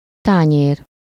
Ääntäminen
Ääntäminen France: IPA: [vɛ.sɛl] Haettu sana löytyi näillä lähdekielillä: ranska Käännös Ääninäyte 1. tányér Suku: f .